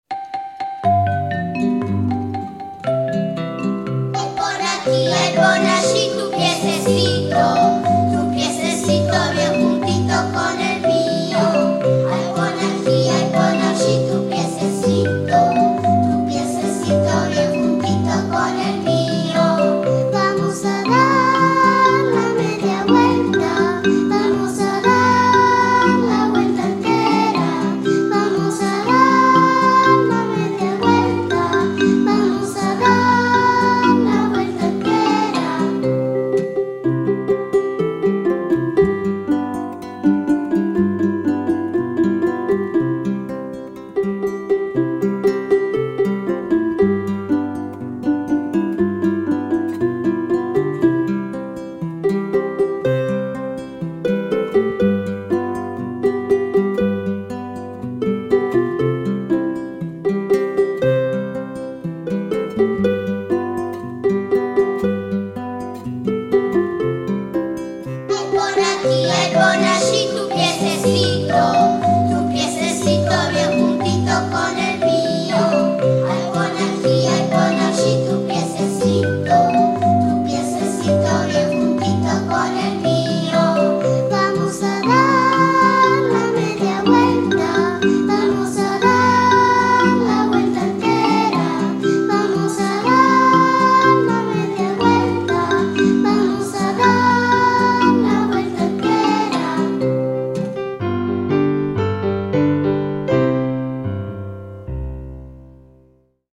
Parabailar